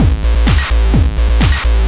DS 128-BPM A3.wav